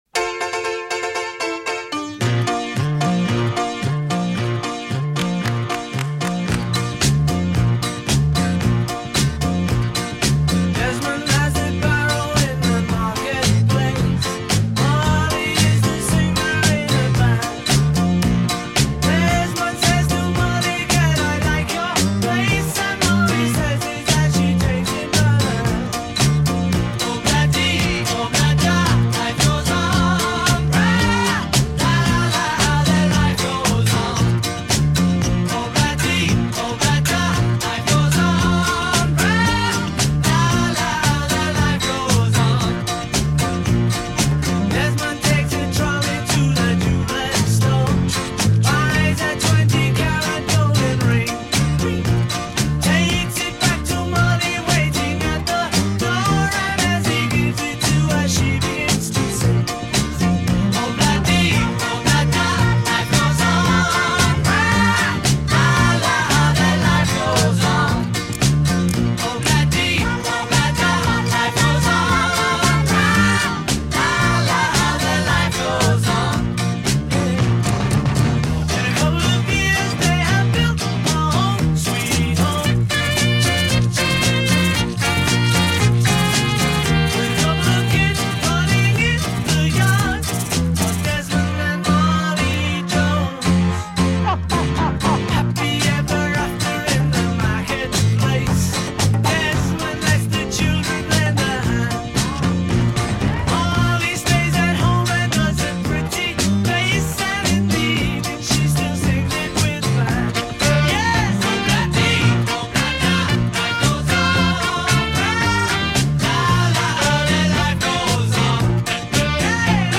в этой песне они смеются в середине и в конце